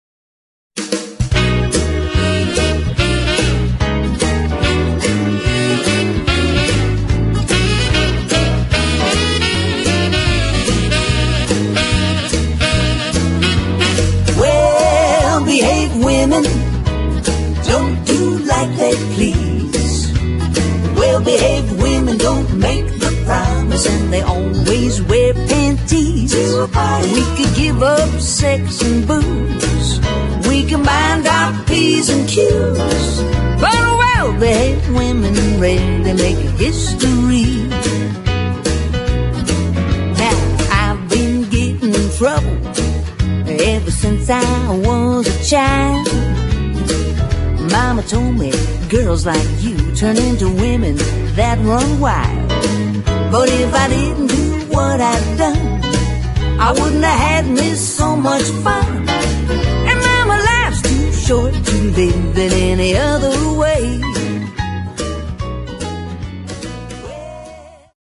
comedy music